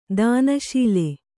♪ dāna śile